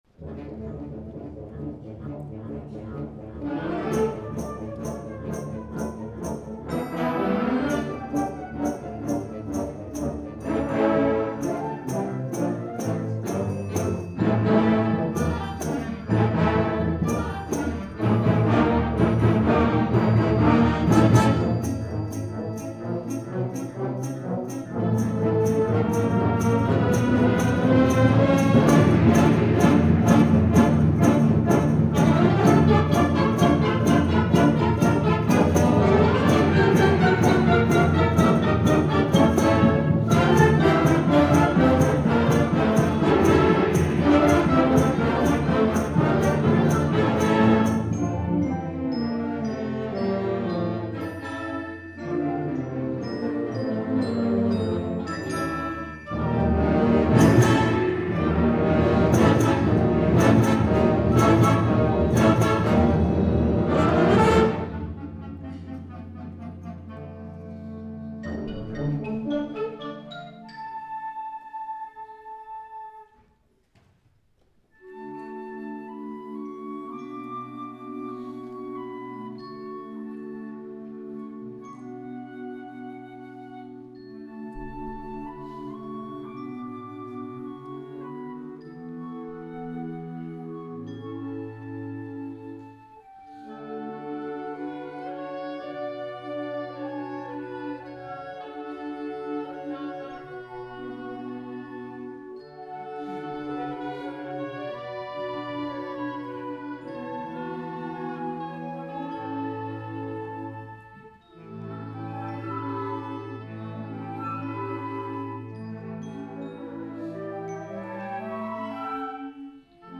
2011 Winter Concert